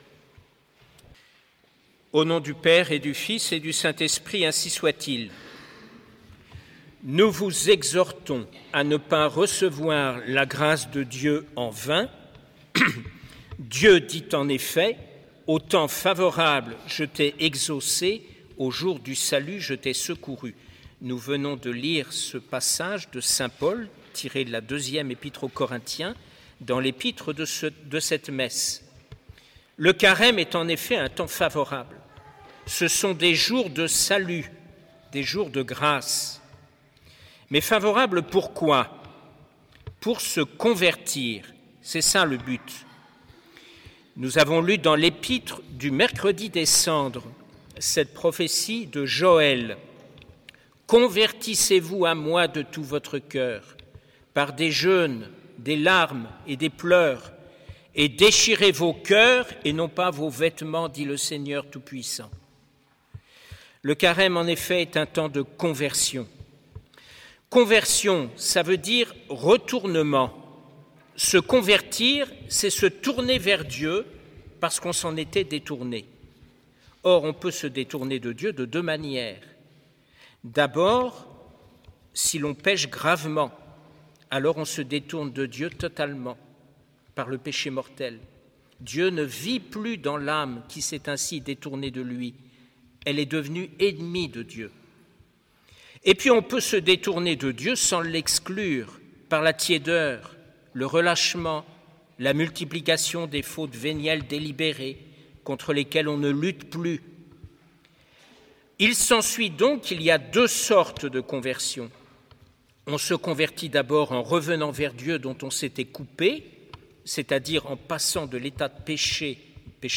Occasion: Premier Dimanche de Carême
Type: Sermons